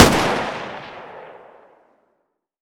ak74_distance_fire1.wav